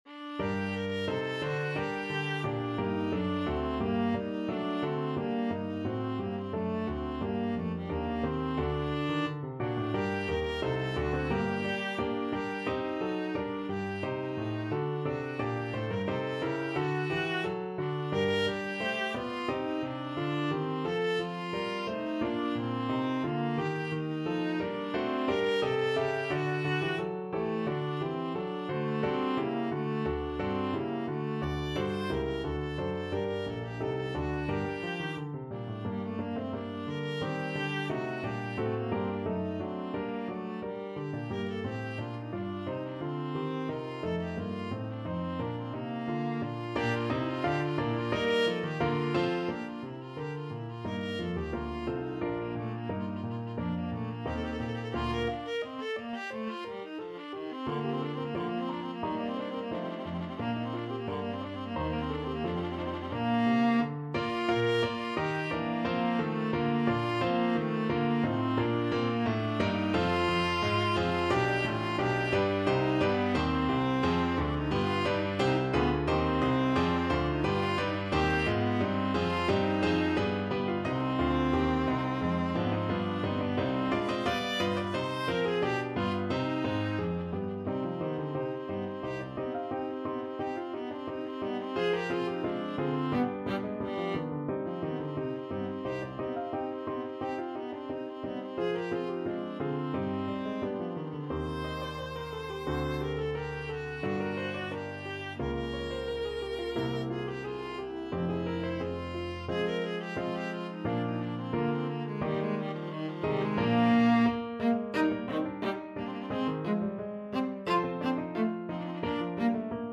2/4 (View more 2/4 Music)
~ = 88 Allegro (View more music marked Allegro)
Classical (View more Classical Viola Music)